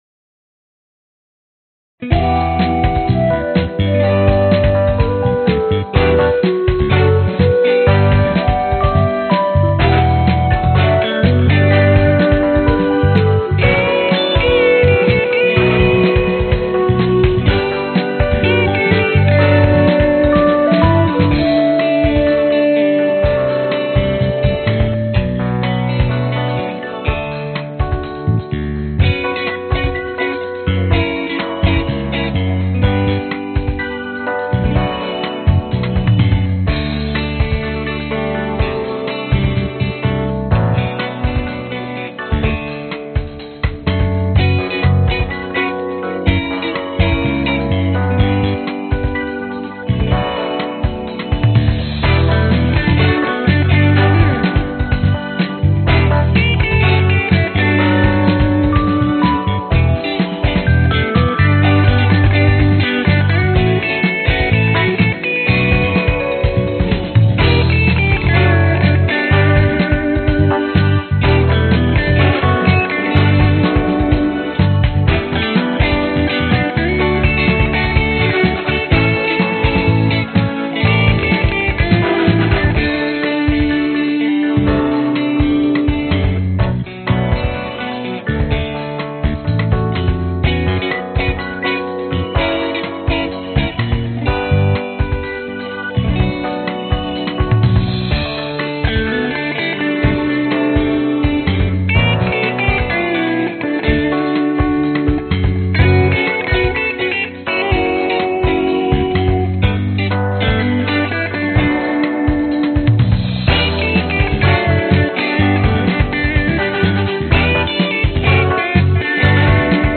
标签： 男声 贝斯 铜管 钢琴 吉他
声道立体声